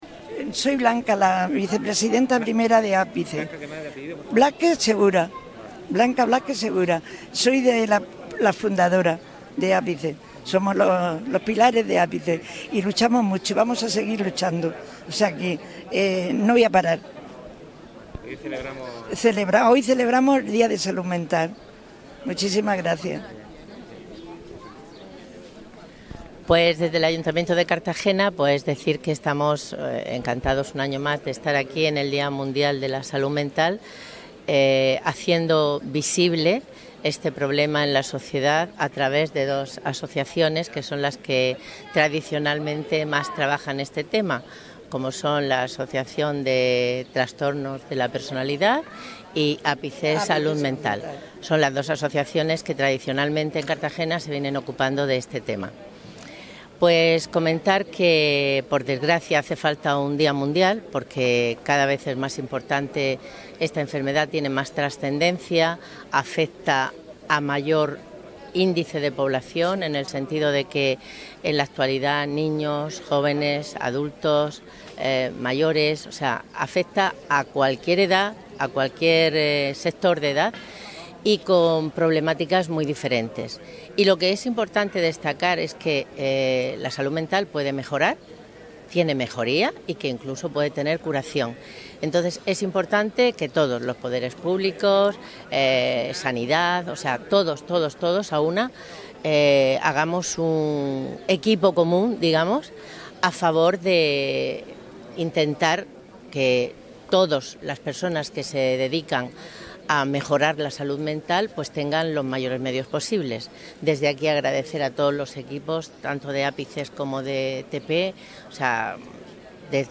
Cartagena, en el 'Día mundial de la salud mental', reivindica la importancia de visibilizar los problemas de salud mental en la juventud, como se ha refrejado en el manifiesto leído hoy en la plaza del Icue por las asociaciones APICES Salud Mental y TP Trastornos de Personalidad.
Enlace a Lectura del manifiesto por el Día Mundial de la Salud Mental